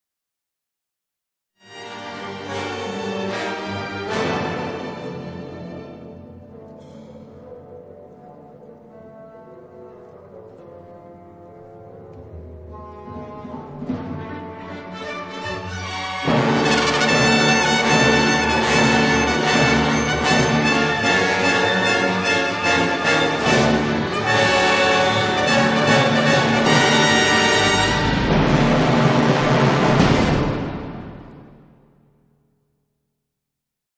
November 2003 Concert